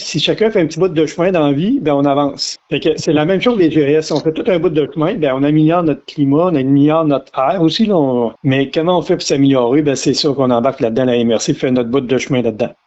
Le préfet, Mario Lyonnais, a rappelé l’importance de faire sa part pour l’environnement.